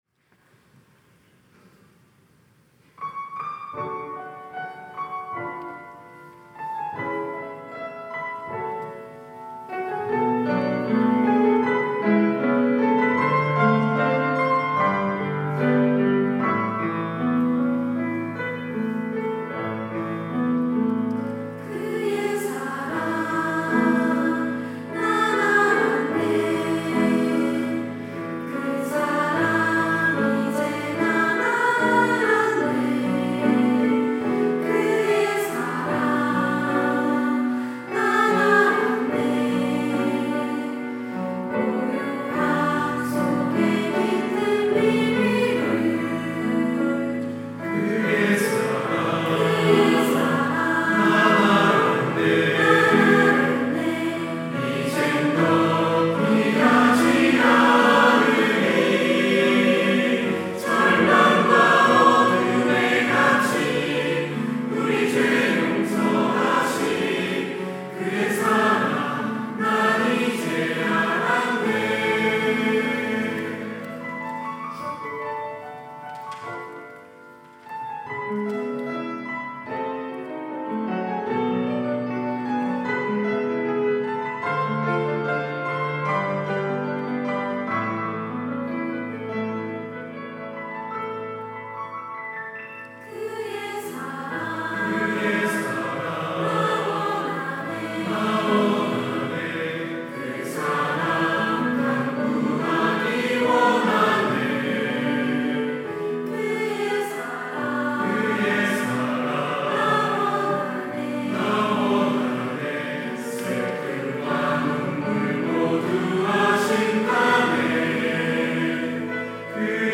특송과 특주 - 그 사랑 이제 난 알았네
청년부 3팀